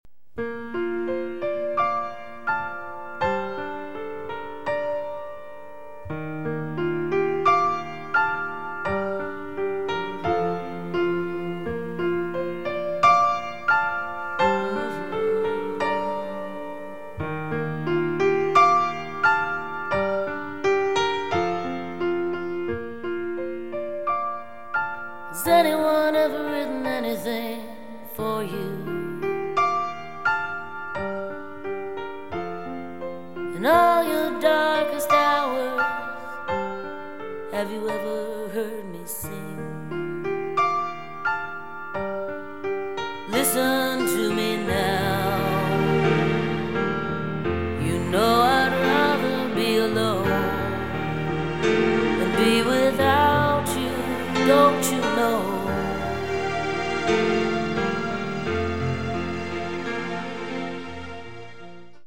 a few short sound bites